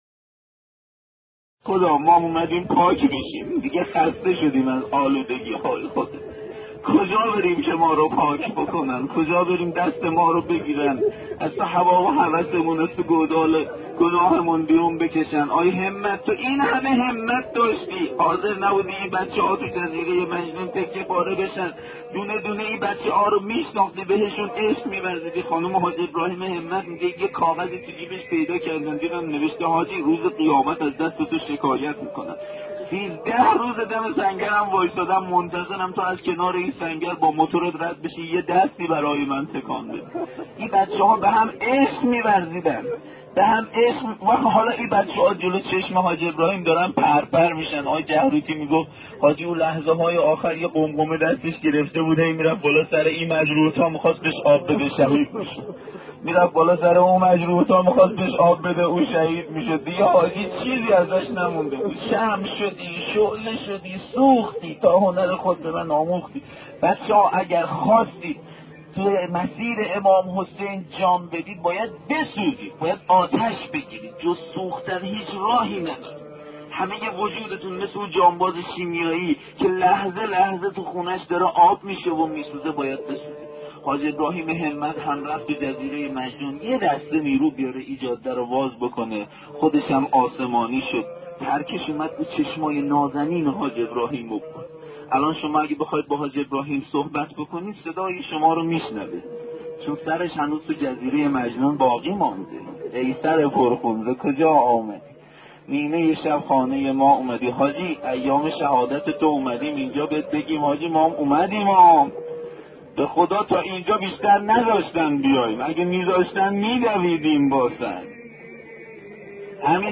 ravayatgari64.mp3